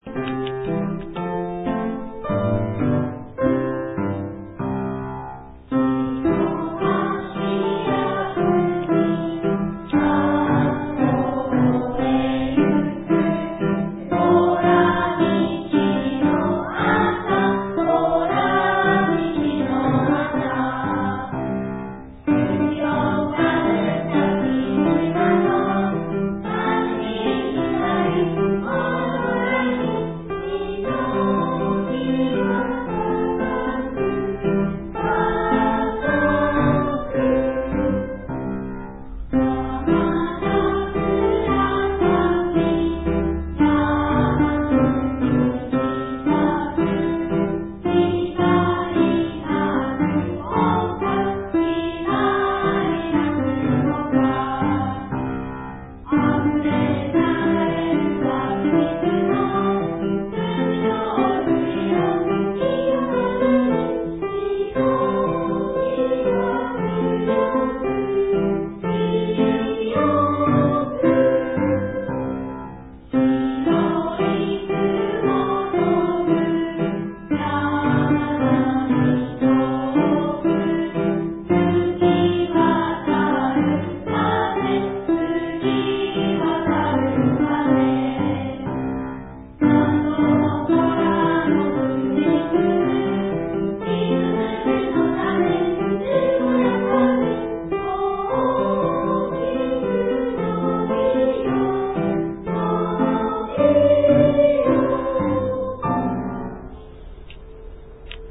【校歌歌声：平成２８年度在校生：１学期終業式】